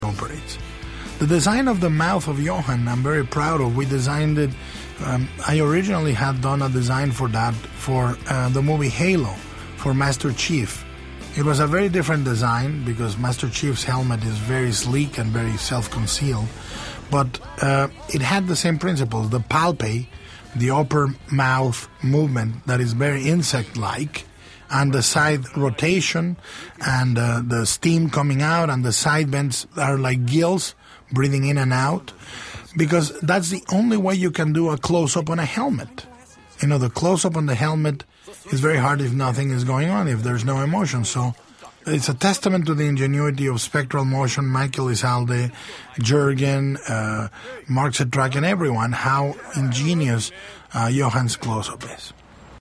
an audio clip (617k, mp3 format) from the Hellboy II director's commentary, during which Guillermo Del Toro discusses the helmet he designed for the character Johann Krauss (you can see it here) - and how this evolved from a design for the Master Chief's helmet when he was thinking about the Halo movie.